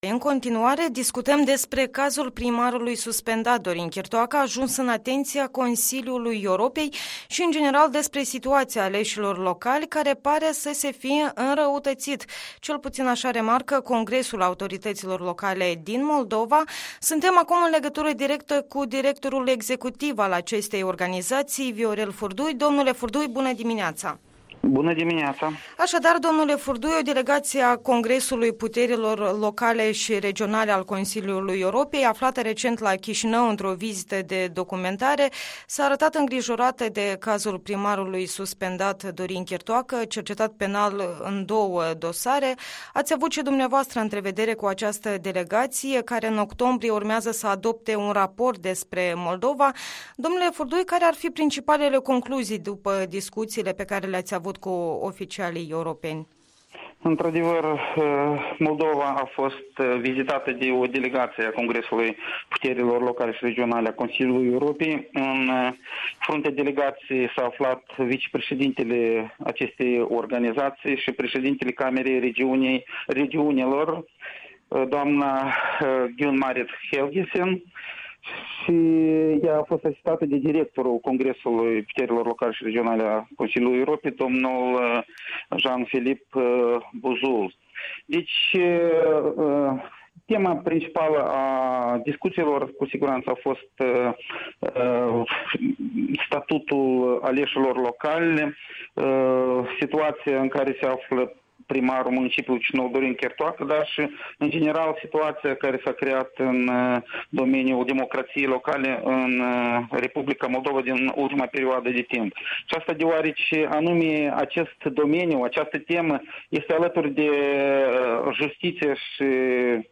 Interviul matinal la radio Europa Liberă.